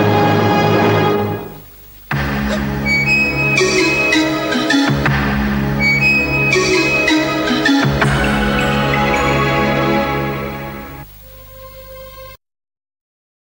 jingle pub